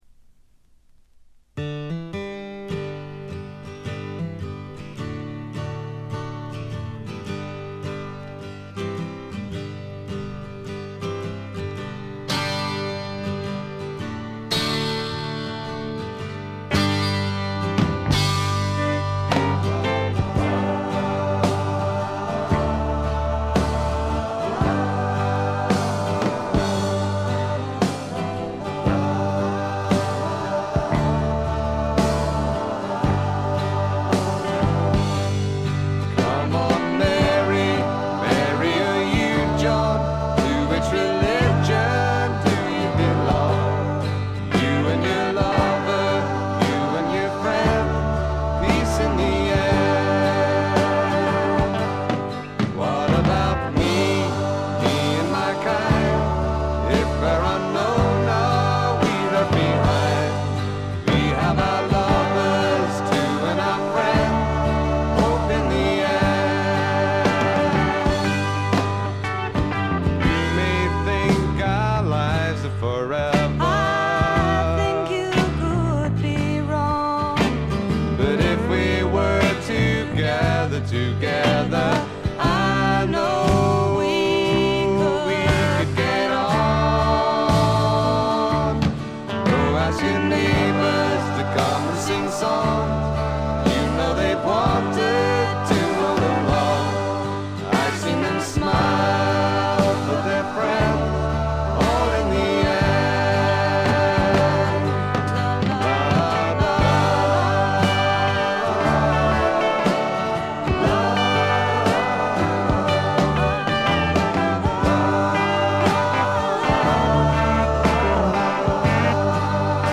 軽微なバックグラウンドノイズ、チリプチ、散発的な軽いプツ音が少し。
英国フォークロックの基本中の基本！！
試聴曲は現品からの取り込み音源です。